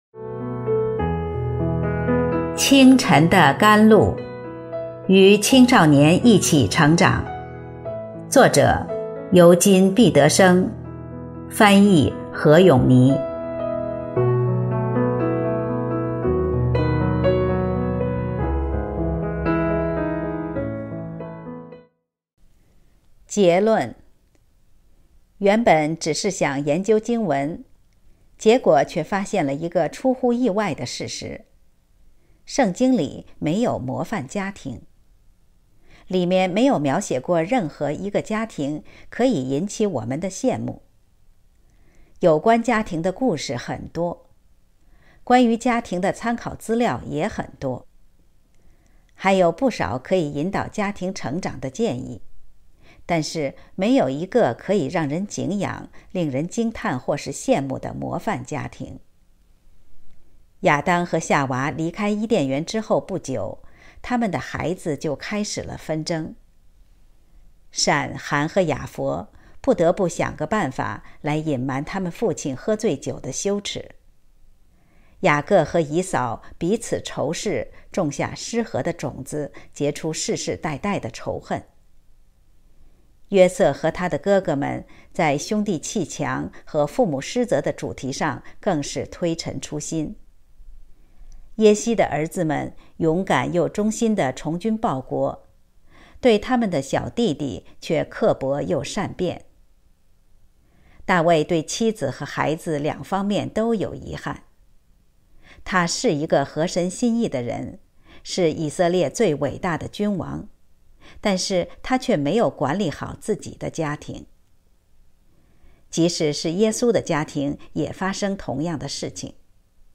首页 > 有声书 | 清晨甘露 | 灵性生活 > 清晨的甘露 ● 与青少年一起成长（14）